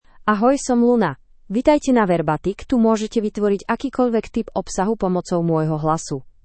LunaFemale Slovak AI voice
Luna is a female AI voice for Slovak (Slovakia).
Voice sample
Female
Luna delivers clear pronunciation with authentic Slovakia Slovak intonation, making your content sound professionally produced.